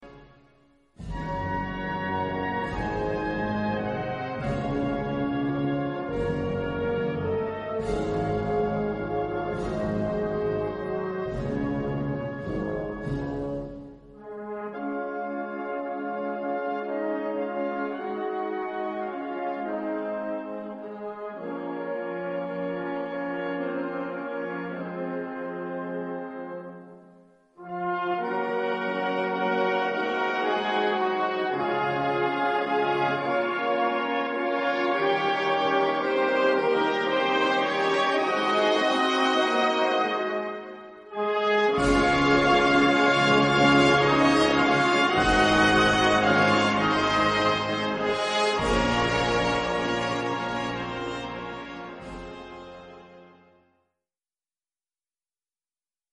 Gattung: Paraphrase
A4 Besetzung: Blasorchester Zu hören auf